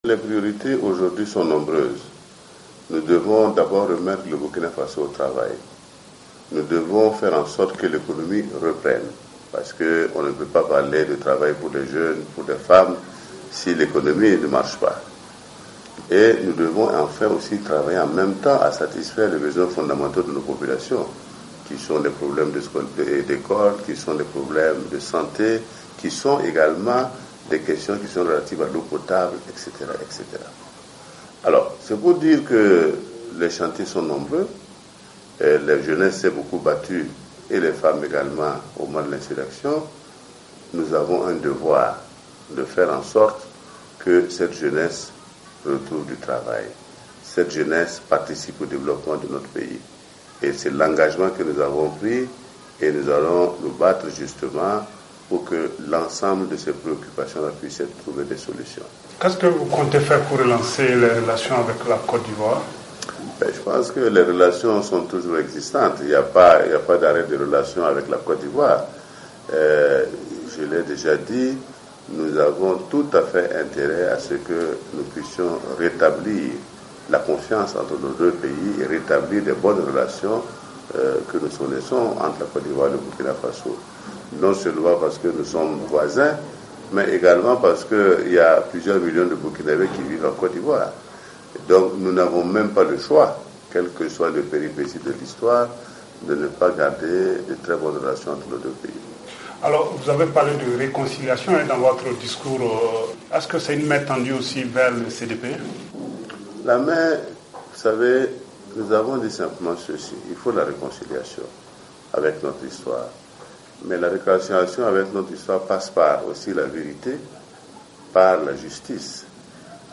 Dans une interview exclusive, le nouveau président du Burkina Faso appelle à la réconciliation avec le CDP mais demande aux anciens alliés de Blaise Compaoré de faire amende honorable.